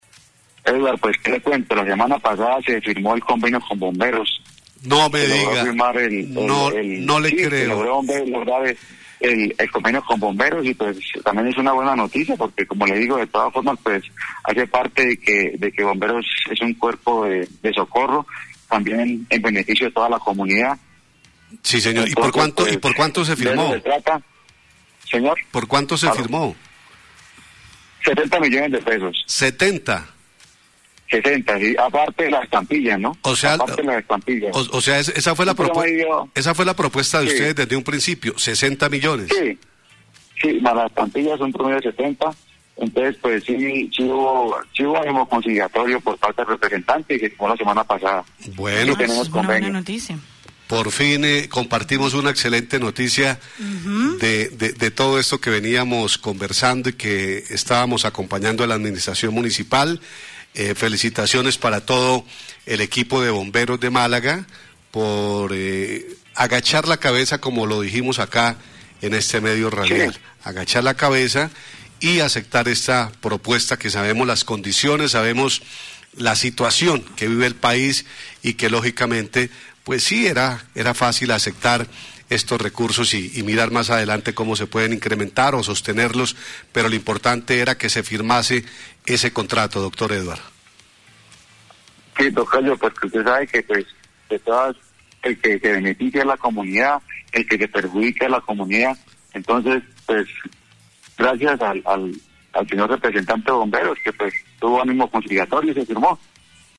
La alcaldía por su parte según el secretario de gobierno no disponía de mas recursos para poder incrementar el valor del convenio, debido a la situación actual que vive el país con el covid 19.  la semana anterior se logro firmar el contrato por los sesenta millones de pesos que le ofrecía la alcaldía inicialmente mas estampillas, para un total de aproximadamente setenta millones de pesos, así lo dio a conocer en entrevista con nuestra emisora el Dr, Edwar Carvajal Secretario de gobierno de la alcaldía de Málaga.